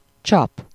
Ääntäminen
Synonyymit capsule embouteillage ralentissement flotteur but file Ääntäminen France: IPA: [bu.ʃɔ̃] Haettu sana löytyi näillä lähdekielillä: ranska Käännös Ääninäyte 1. vízcsap 2. dugó 3. csap 4. tömítés Suku: m .